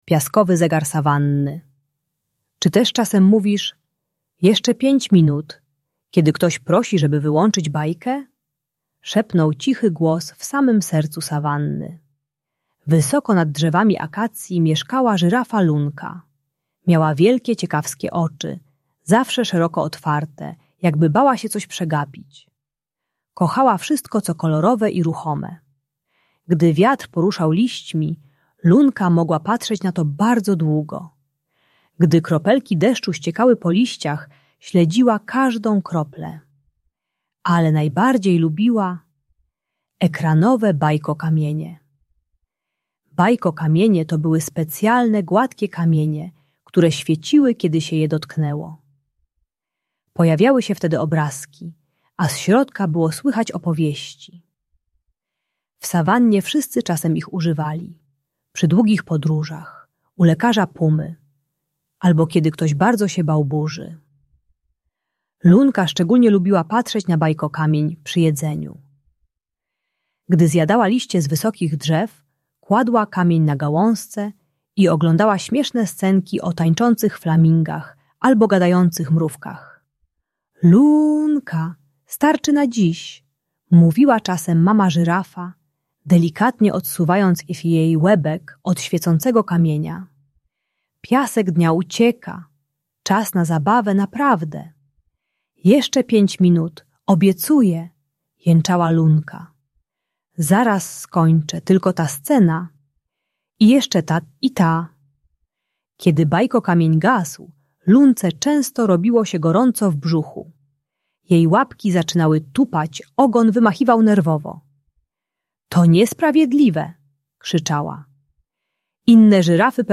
Uczy techniki głębokiego oddychania i samoregulacji emocji przy odstawianiu ekranów. Audiobajka o ograniczaniu czasu przed ekranem bez awantur.